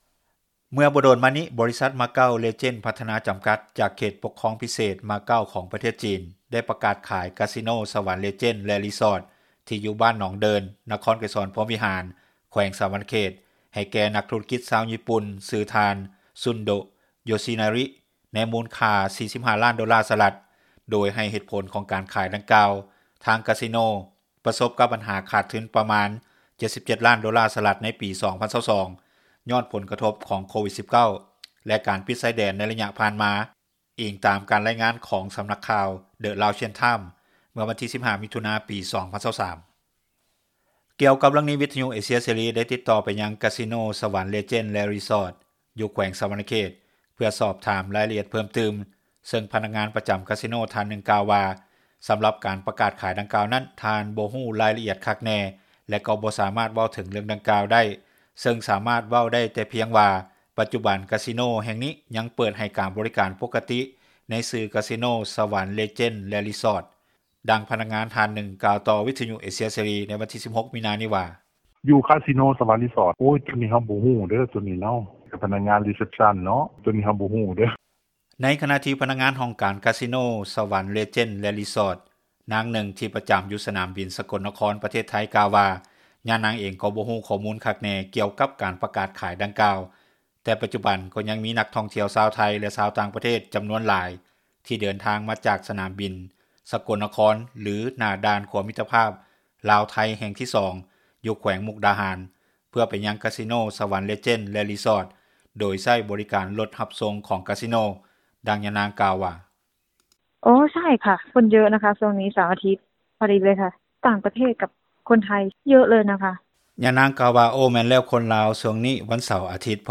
ດັ່ງພະນັກງານທ່ານນຶ່ງ ກ່າວຕໍ່ວິທຍຸ ເອເຊັຽ ເສຣີ ໃນວັນທີ 16 ມິຖຸນາ ນີ້ວ່າ: